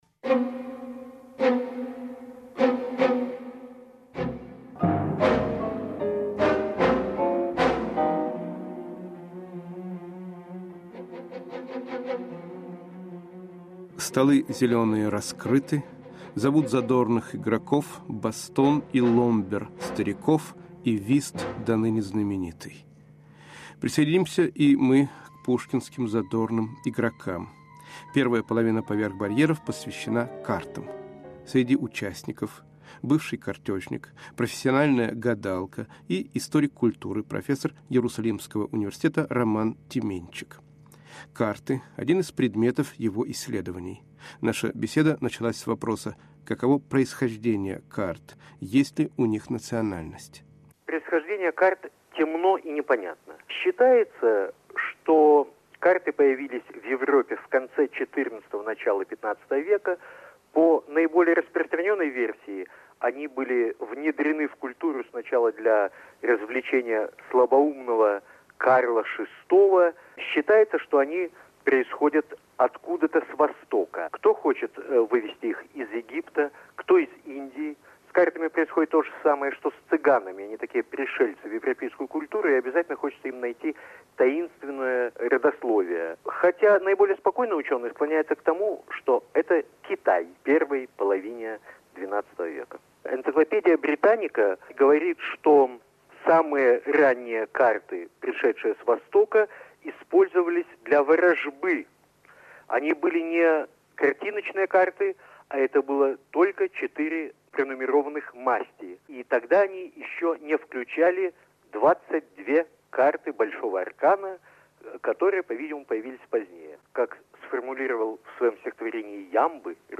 О картах говорят историк культуры, любитель карточной игры и гадалка